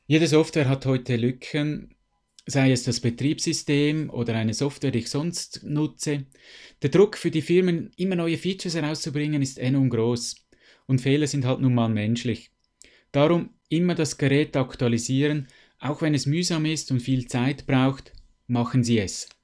Dieses Interview gibt es auch auf Schwitzerdütsch!!